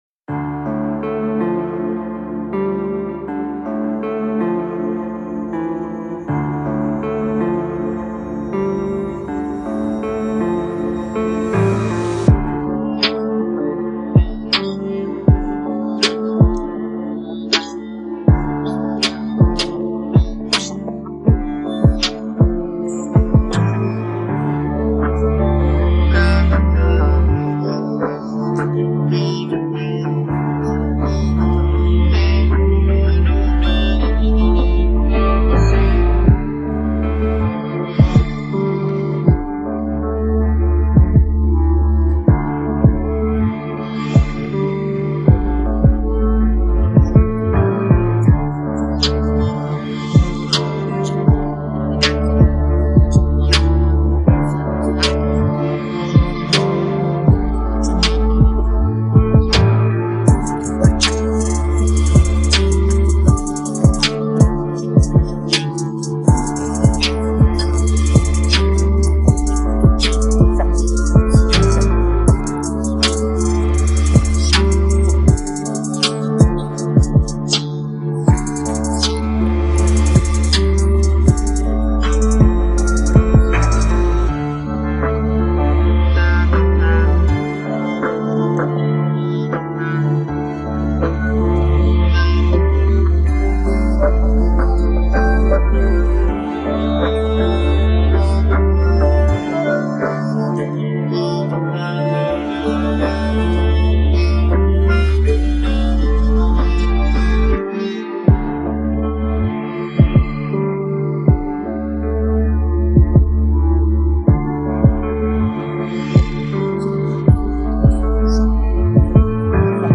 download-cloud دانلود نسخه بی کلام (KARAOKE)